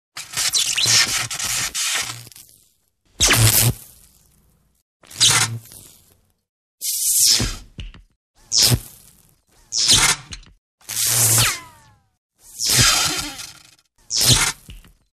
Звуки электричества
Звук электрического разряда или перегоревшего прибора из-за короткого замыкания